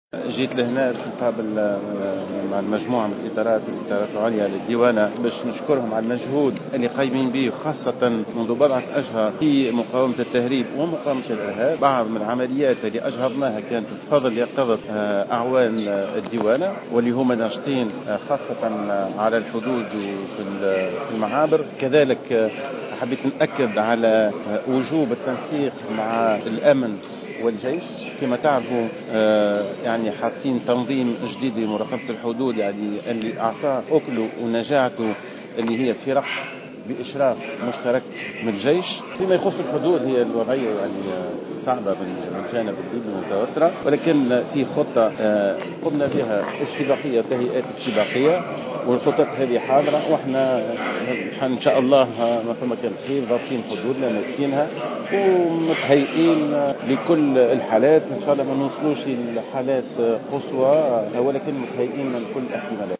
أكد رئيس الحكومة المؤقتة مهدي جمعة في كلمة له على هامش إشرافه على الاحتفال بالذكرى الثامنة والخمسين للديوانة التونسية بقصر قرطاج على أهمية الدور الهام الذي يضطلع به سلك الديوانة مضيفا أنّ الدولة جاهزة لكل الاحتمالات على الحدود مع ليبيا وفق استعدادات مدروسة، على حدّ تعبيره.